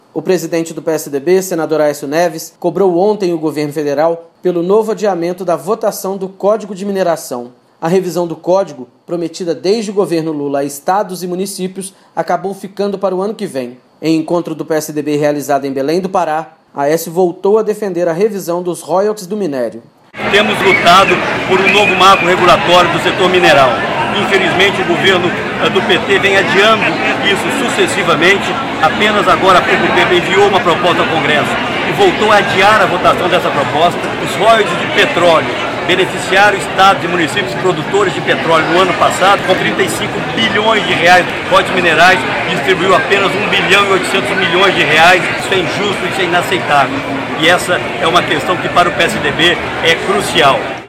Boletim